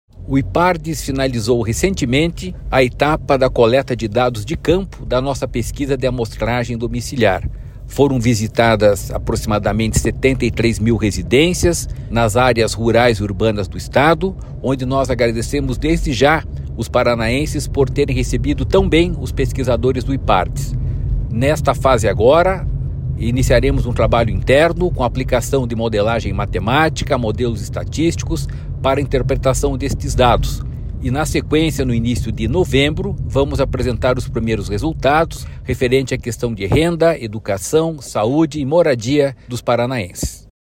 Sonora do diretor-presidente do Ipardes, Jorge Callado, sobre a conclusão da Pesquisa por Amostra de Domicílios do Paraná